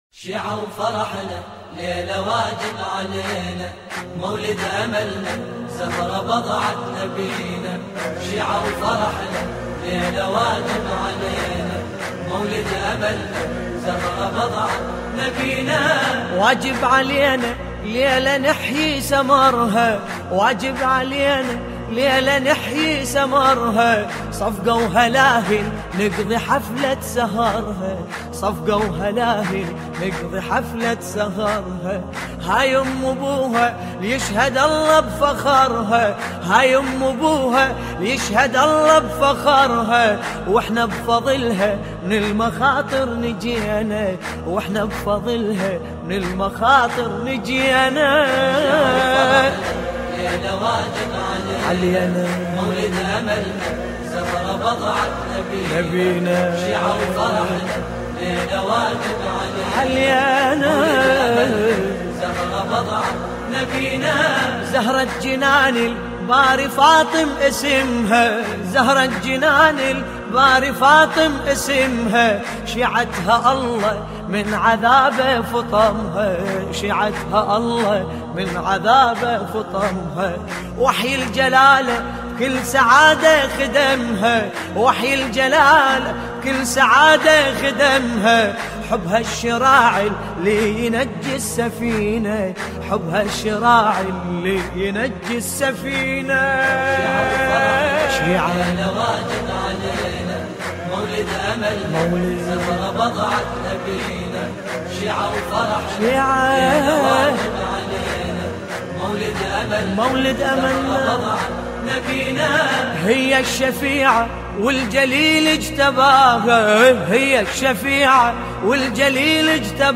نماهنگ زیبای عربی
ویژه جشن ولادت حضرت فاطمه سلام الله علیها